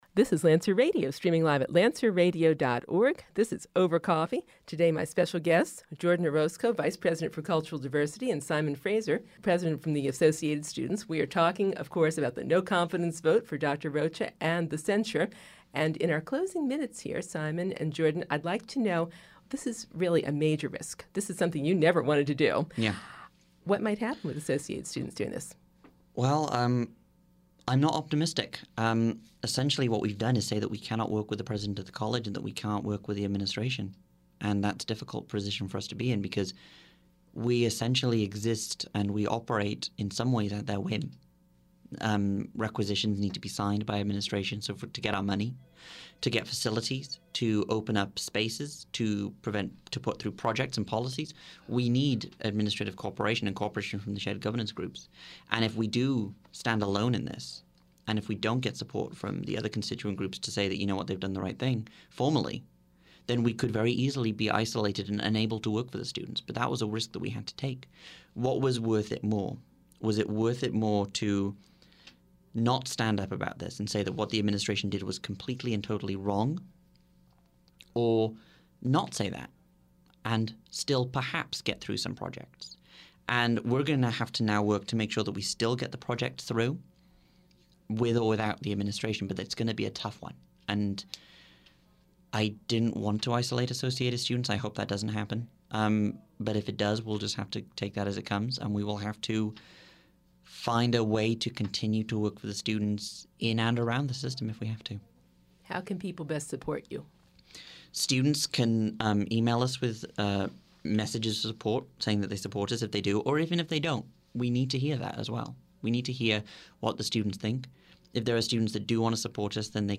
ASPCC Interview, Part Three